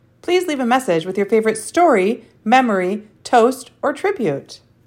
generic greeting on the phone.
generic-greeting.mp3